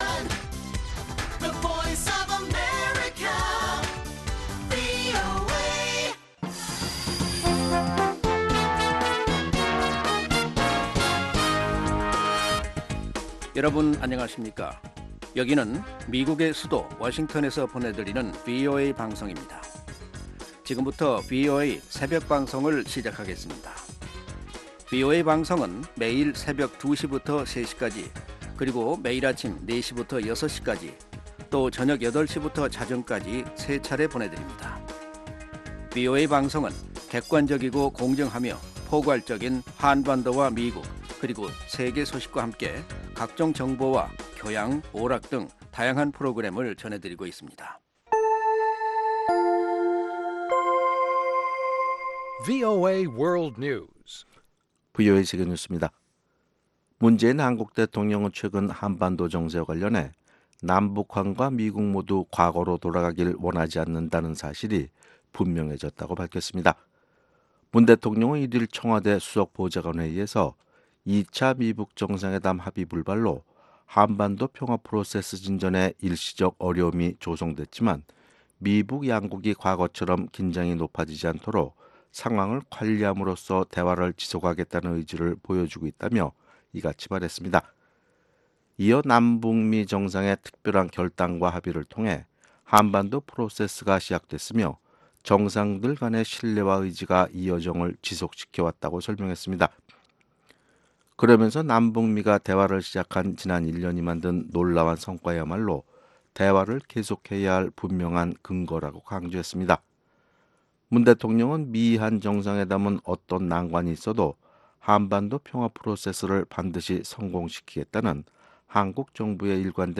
VOA 한국어 '출발 뉴스 쇼', 2019년 4월 2일 방송입니다. 트럼프 대통령은 최근 대북제재 철회 결정을 내린 이유를 김정은 위원장과 좋은 관계를 갖고 있기 때문이라고 말했습니다. 마이크 폼페오 미 국무장관은 북한의 비핵화에 많은 시간이 걸릴 것이라며 미-북 정상의 만남은 너무 늦게 이뤄지지 않길 바란다고 밝혔습니다.